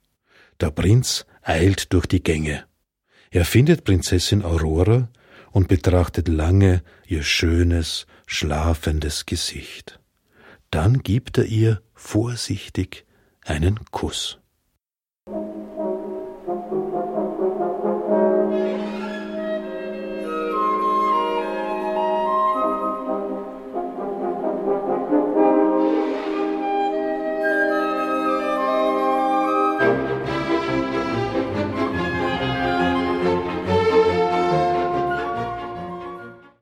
Märchenballett